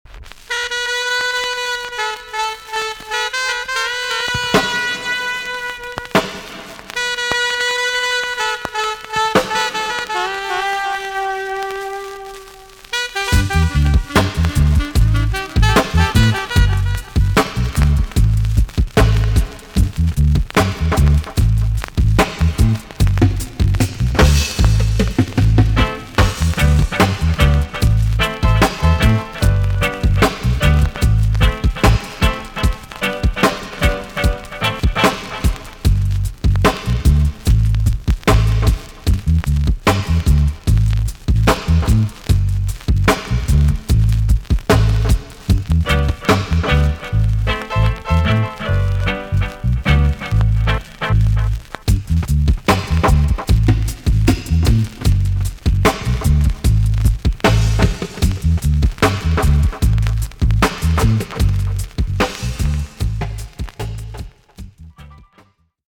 TOP >80'S 90'S DANCEHALL
B.SIDE Version
VG+ 少し軽いチリノイズが入ります。